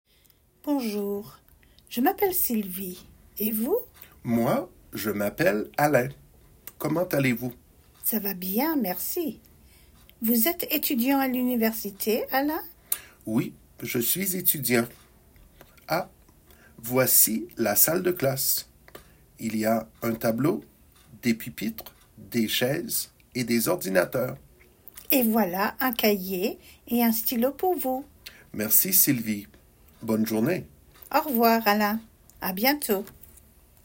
Dialogue – Un 1 | FrenchGrammarStudio